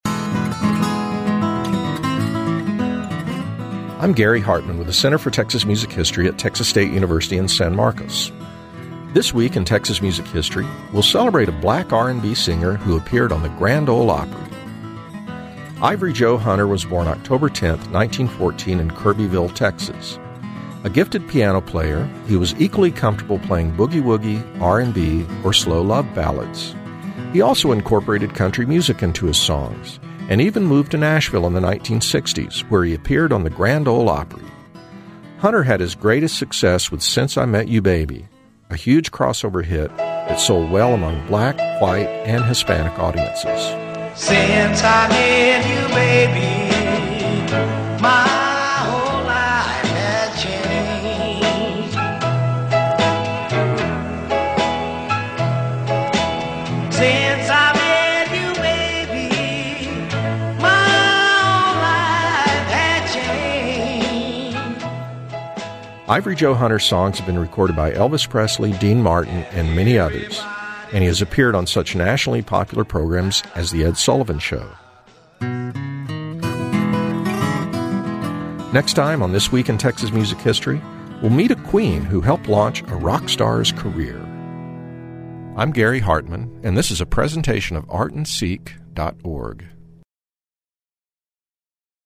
You can also hear This Week in Texas Music History on Friday on KXT and Saturday on KERA radio.